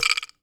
wood_block_rattle_movement_02.wav